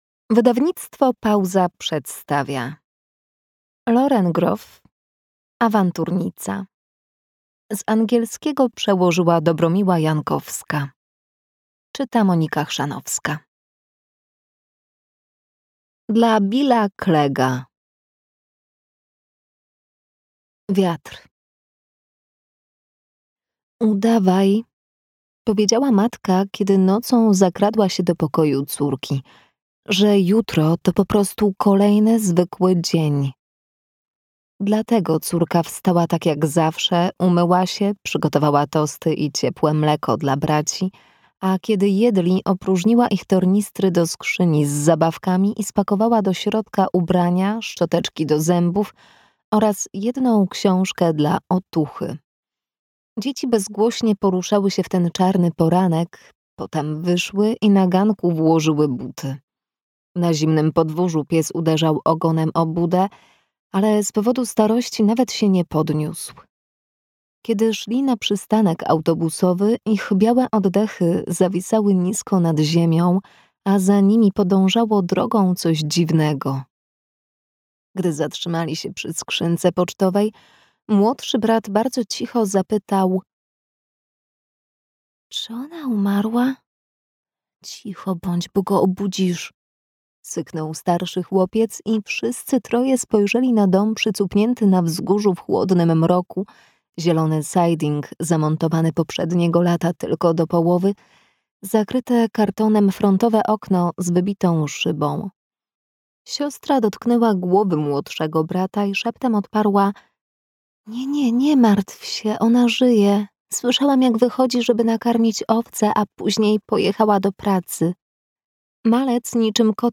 Awanturnica (audiobook) – Wydawnictwo Pauza